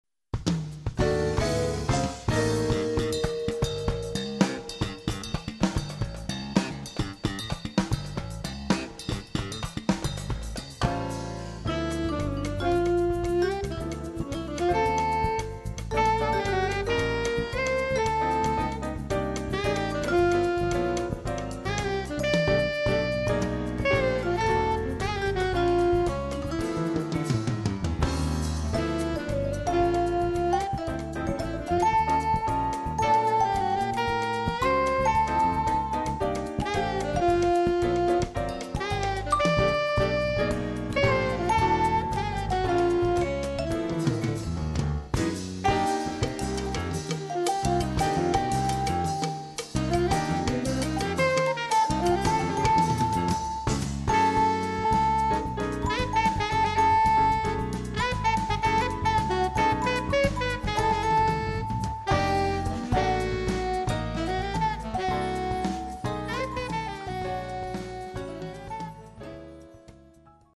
Category: combo (sextet)
Style: samba
Solos: vibes, alto
Instrumentation: combo (sextet) vibes, alto, rhythm (4)